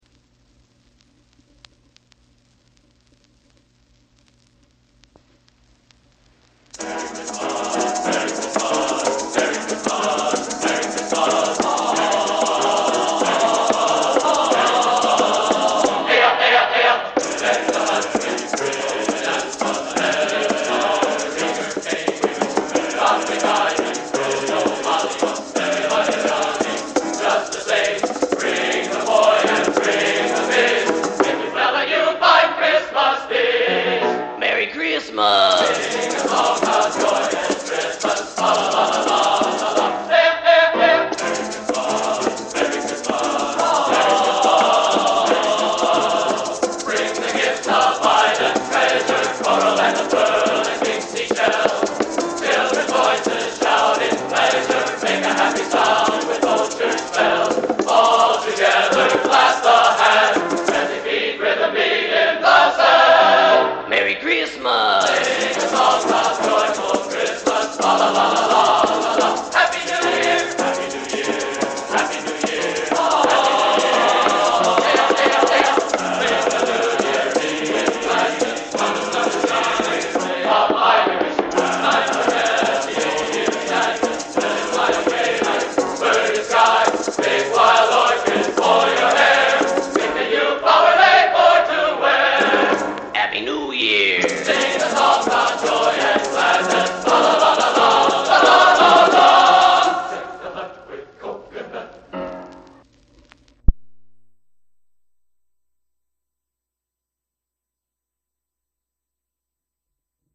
Genre: Holiday | Type: Christmas Show